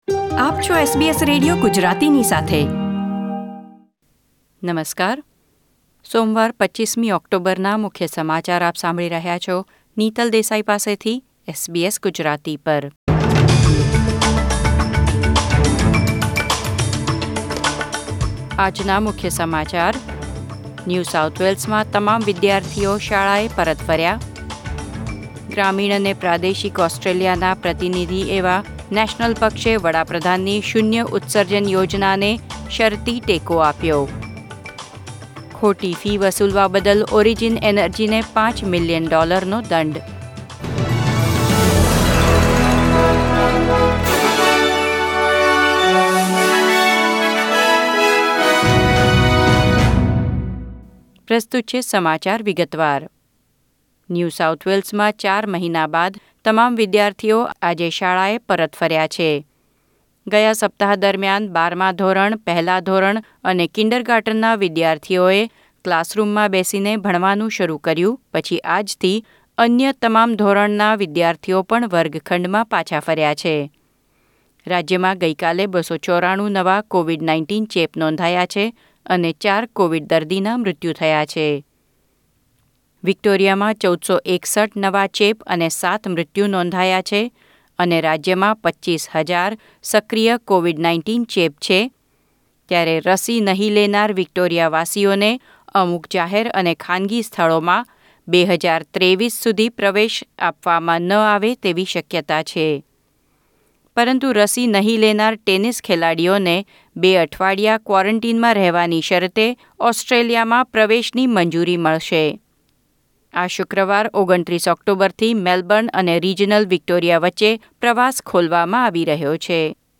SBS Gujarati News Bulletin 25 October 2021